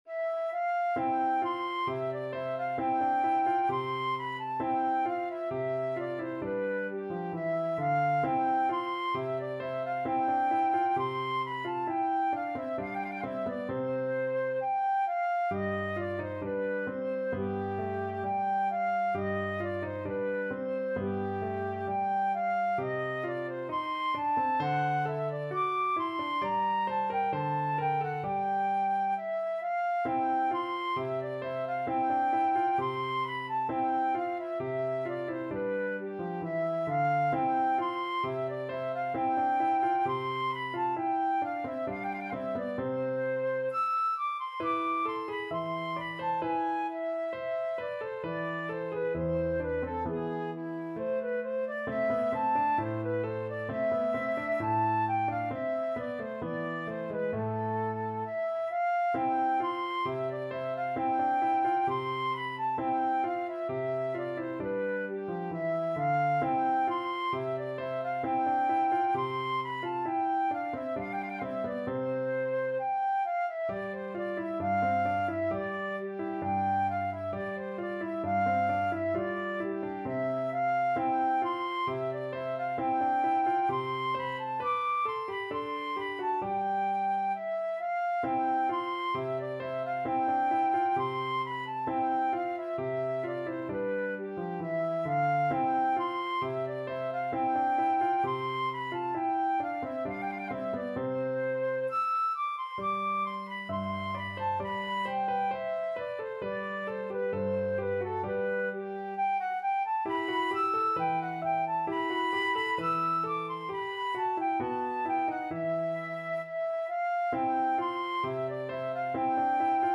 Flute
C major (Sounding Pitch) (View more C major Music for Flute )
~ = 100 Allegretto =c.66
Classical (View more Classical Flute Music)